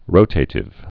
(rōtātĭv)